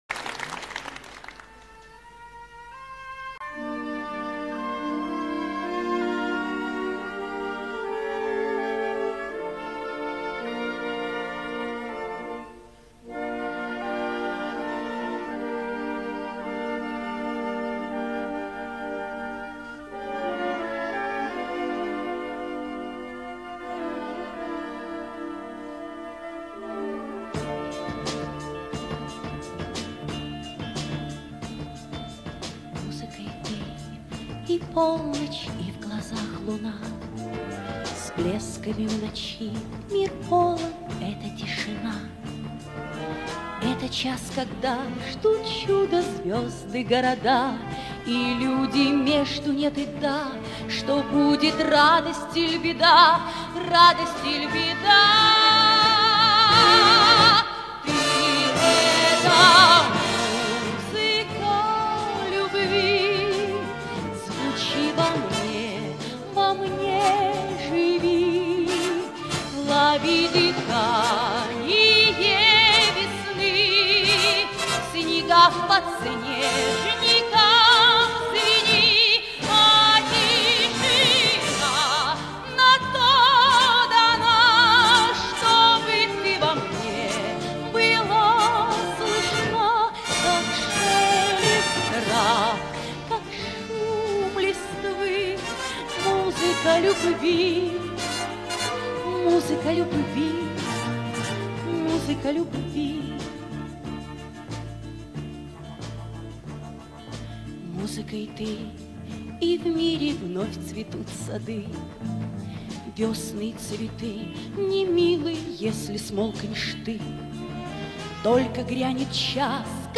Да нет, это фильм-концерт 1987 г.